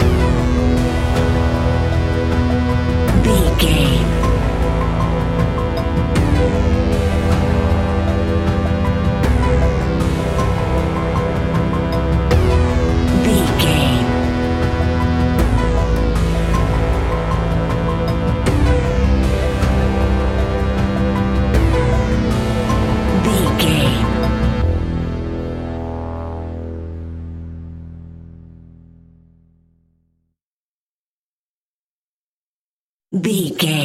In-crescendo
Aeolian/Minor
scary
ominous
dark
haunting
eerie
synthesizer
ticking
electronic music
electronic instrumentals
Horror Pads
Horror Synths